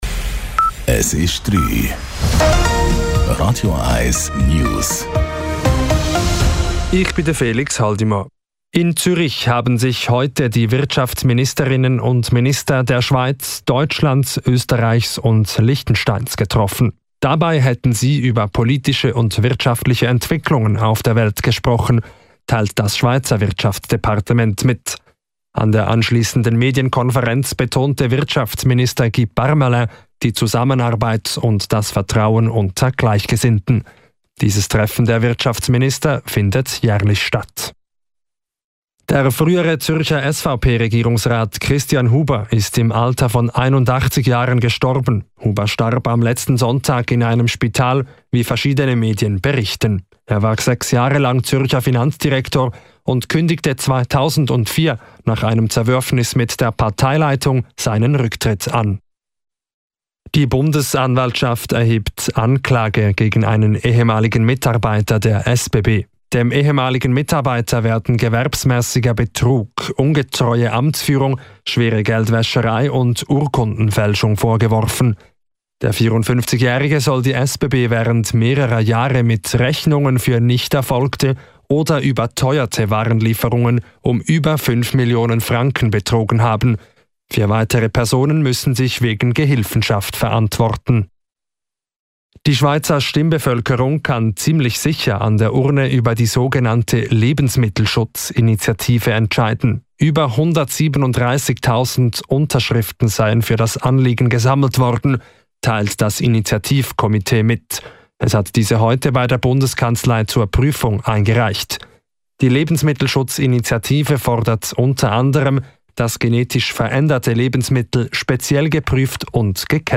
Die letzten News von Radio 1